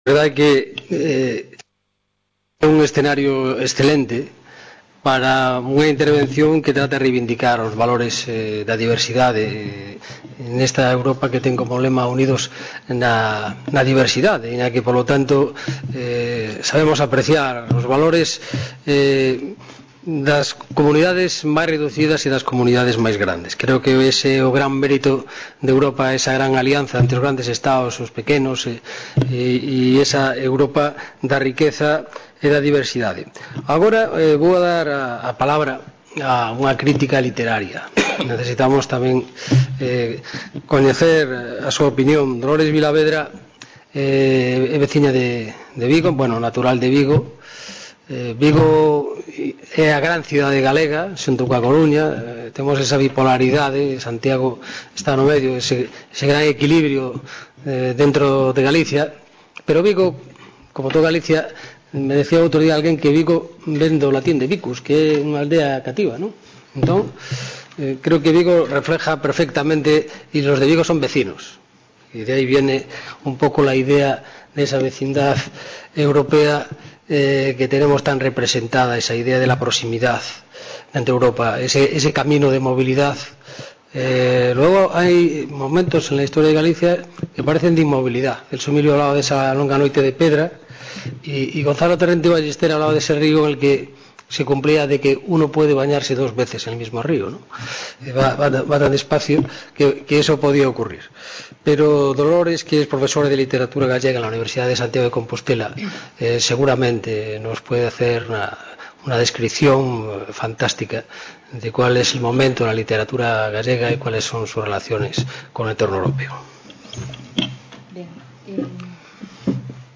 Mesa redonda: Literatura gallega y Europa
Reunion, debate, coloquio...